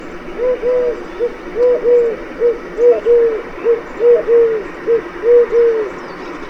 Eurasian Collared Dove
Streptopelia decaocto